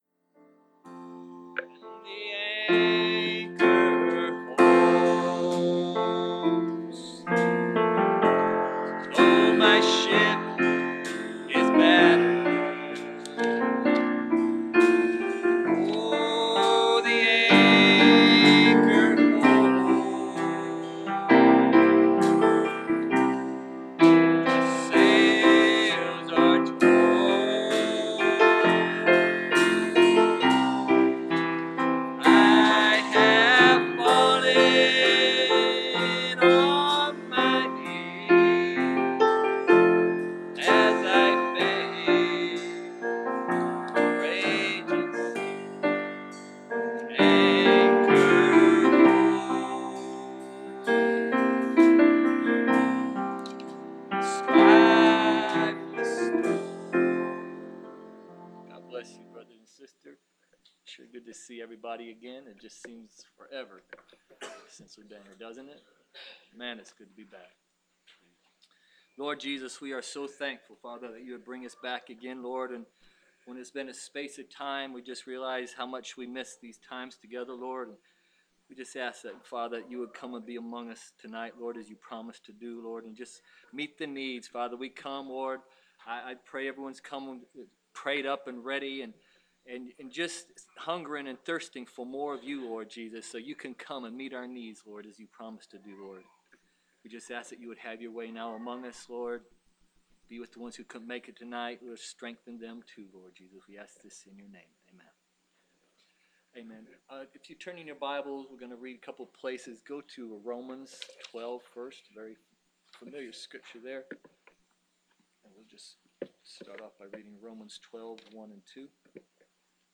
Preached January 28, 2016